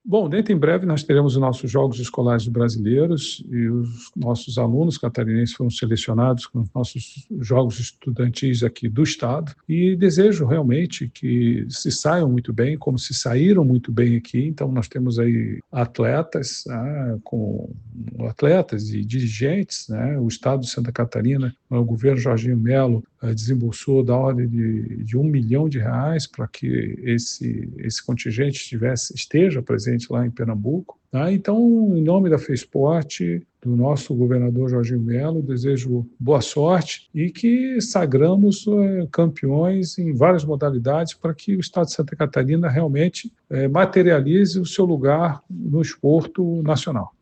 O presidente da Fesporte, Freibergue Nascimento, fala da importância do esporte entre os jovens e do apoio do Governo do Estado para a competição: